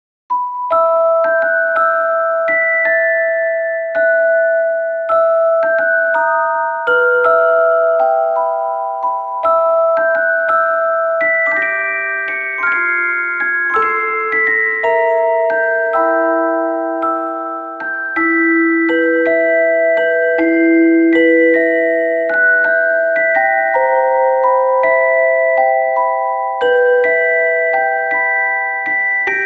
• Качество: 320, Stereo
мелодичные
саундтреки
спокойные
без слов
инструментальные
колокольчики
колыбельные